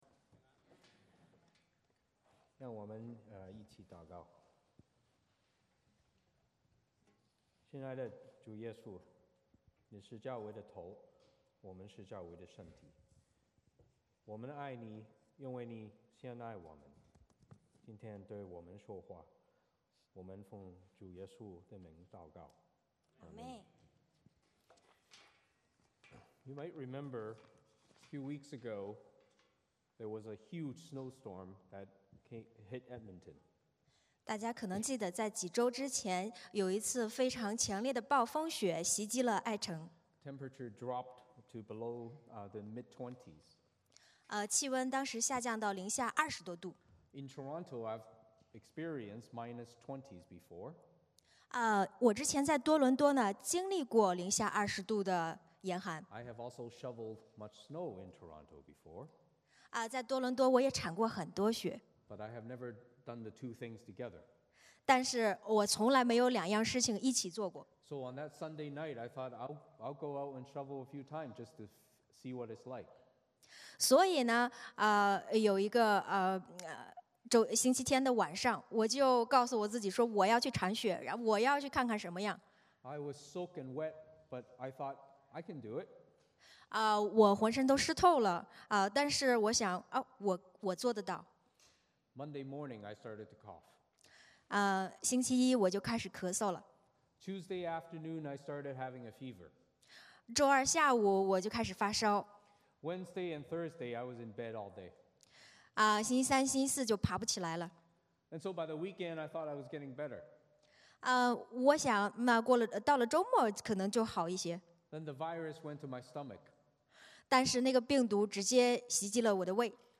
Passage: 以弗所书 4:4-16 Service Type: 主日崇拜 欢迎大家加入我们的敬拜。